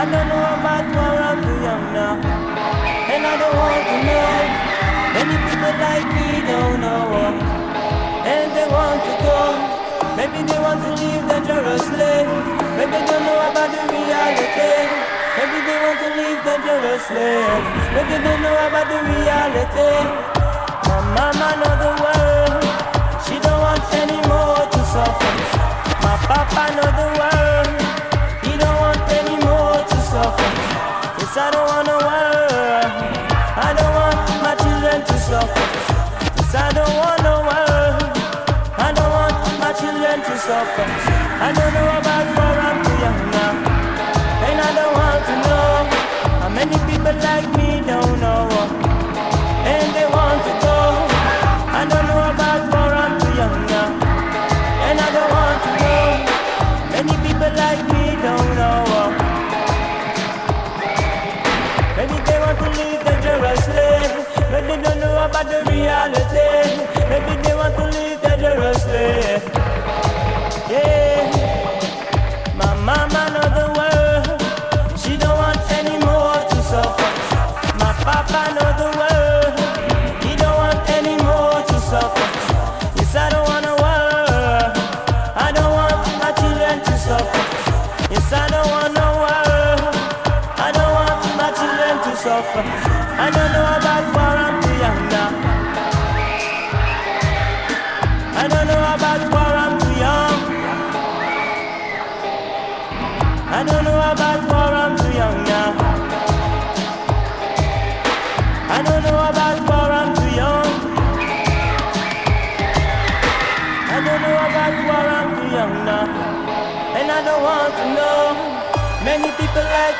物悲しくもキャッチーなアラビック音階メロディ